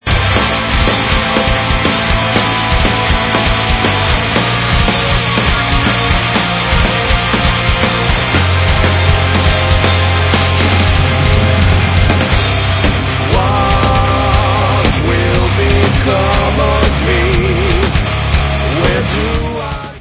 Hard Rock